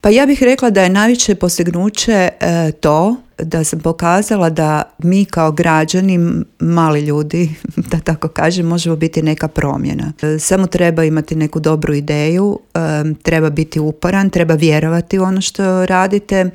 gostovala je u Intervjuu Media servisa u kojem je govorila o svemu što stoji iza ove prestižne nagrade te najpoznatijim projektima udruge